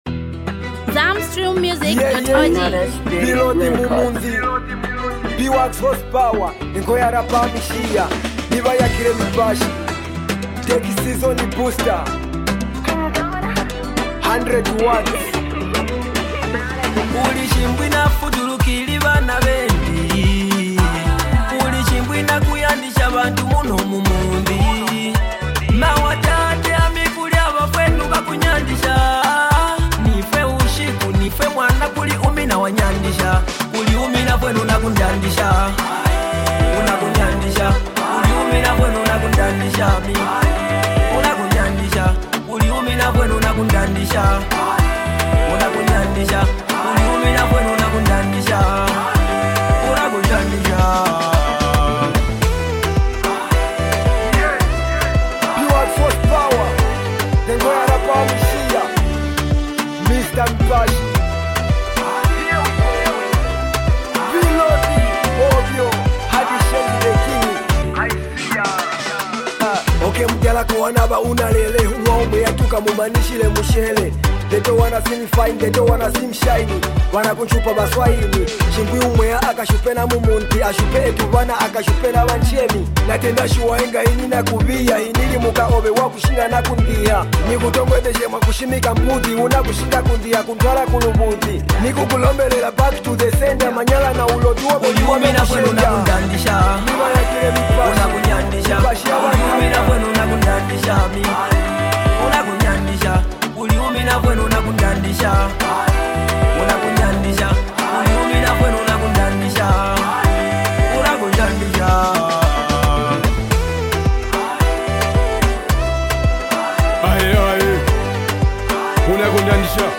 Rapper
It’s a soulful song, a must listen song.